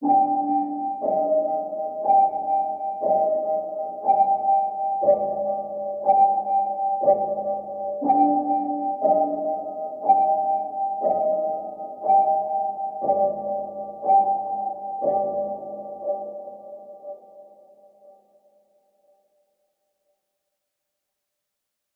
AV_Occult_Piano_60bpm_Bbmin
AV_Occult_Piano_60bpm_Bbmin.wav